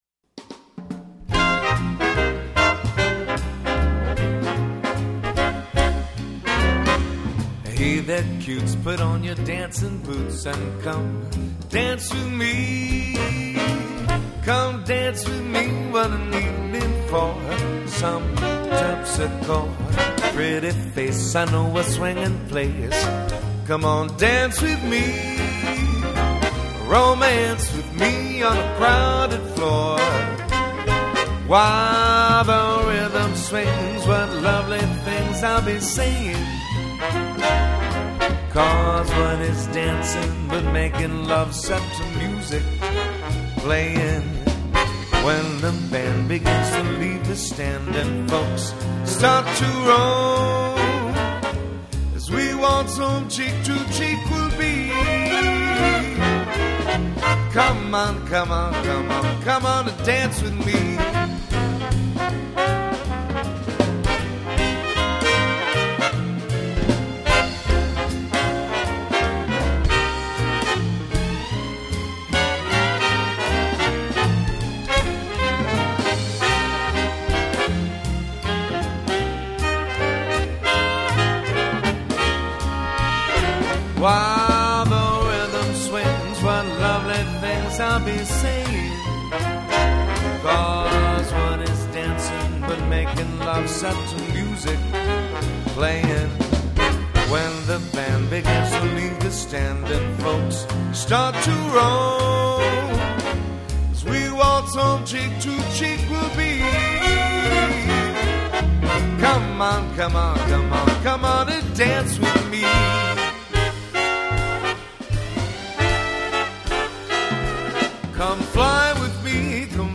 9 piece band